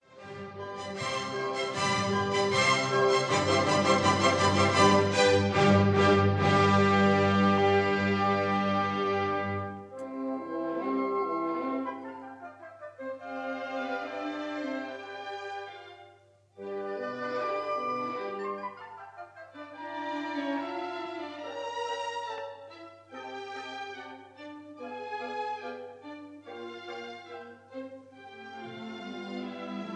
Allegro Vivace